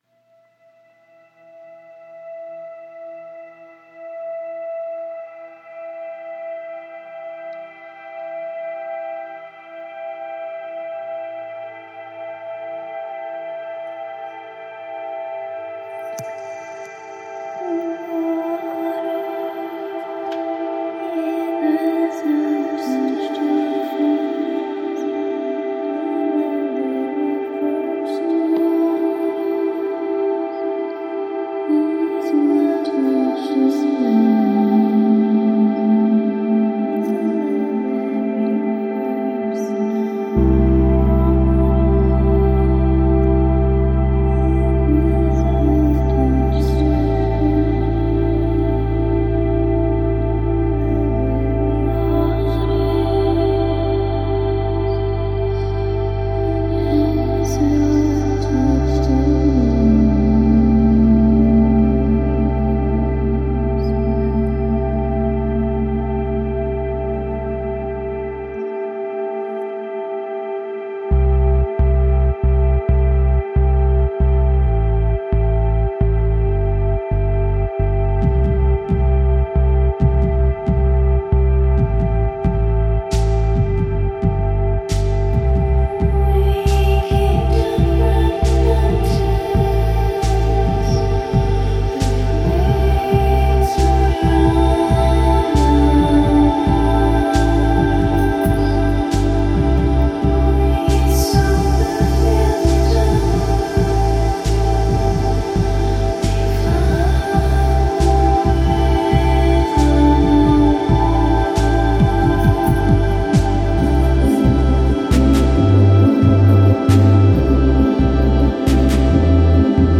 A musical composition
working in her field recording from the island of Delos